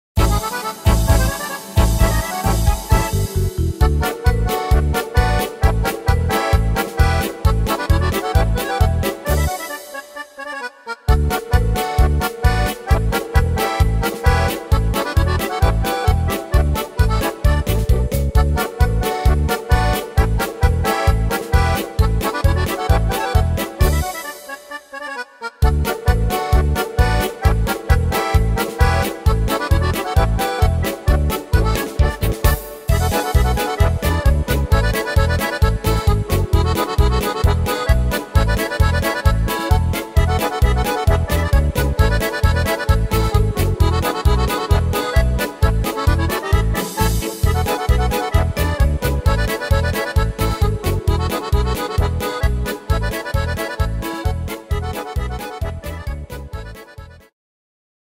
Tempo: 132 / Tonart: D-Dur